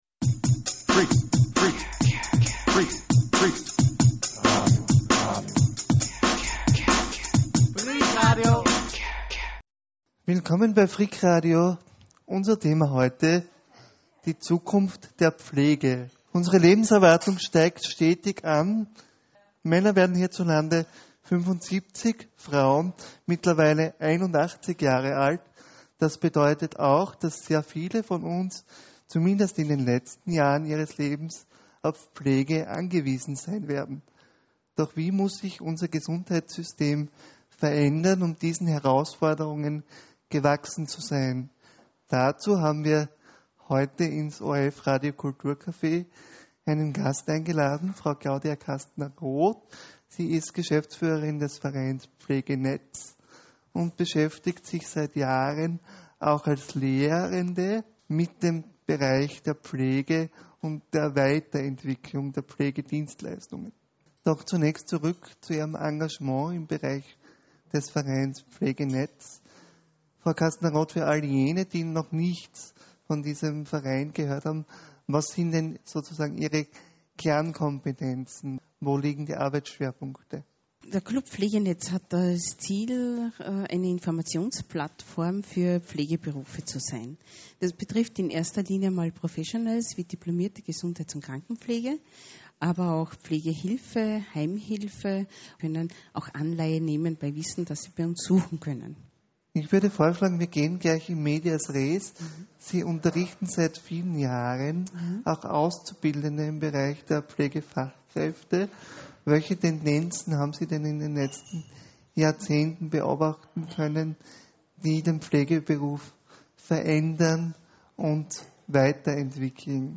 Freak Online - die Webplattform von Freak Radio, dem Radio über den barrierefreien Lifestyle.